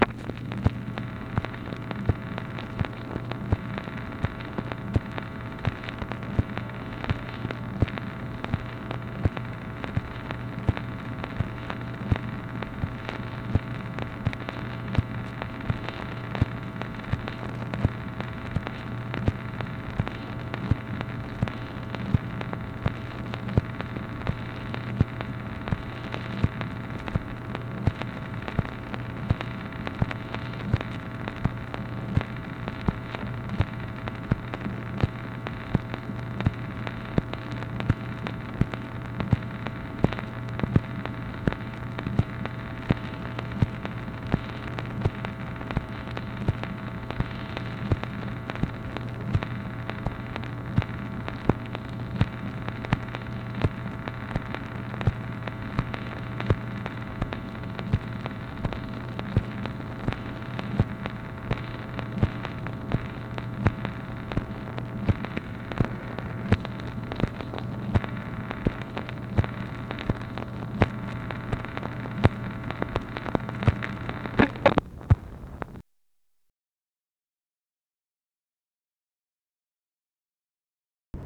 MACHINE NOISE, May 31, 1965
Secret White House Tapes | Lyndon B. Johnson Presidency